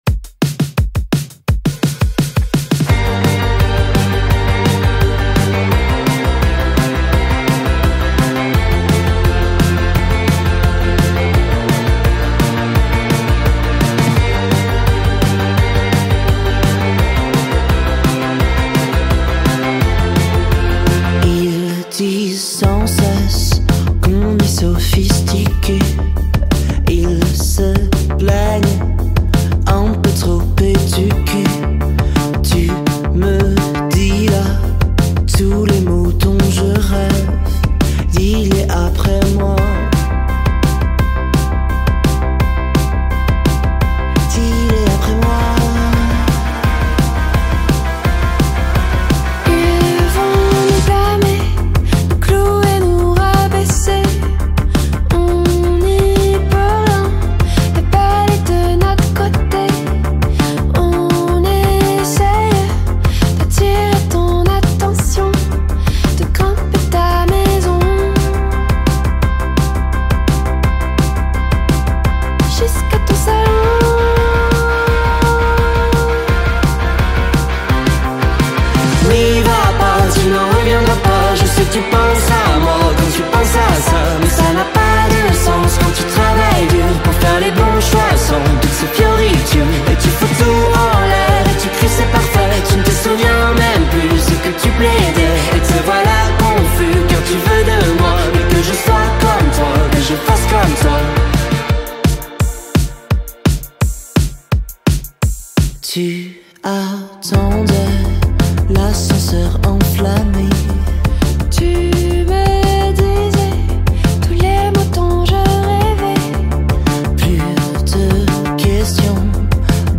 Un groupe local à découvrir